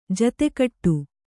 ♪ jate kaṭtu